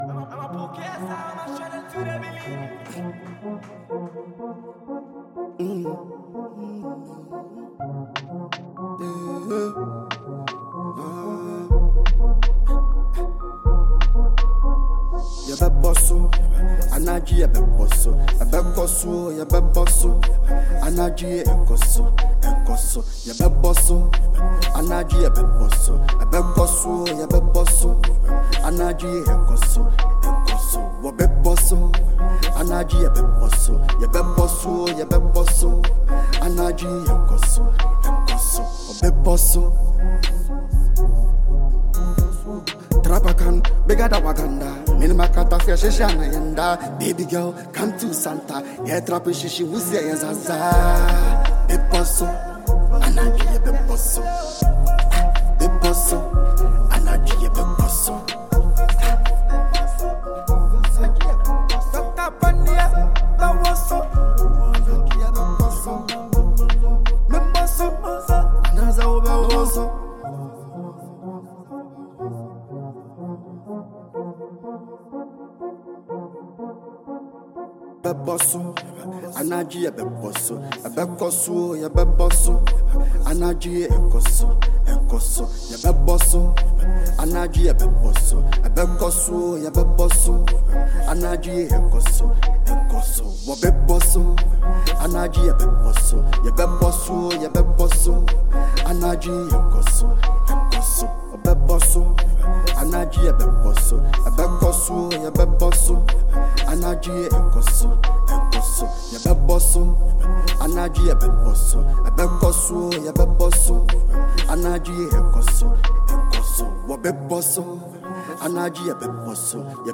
catchy choruses and lyrical profundity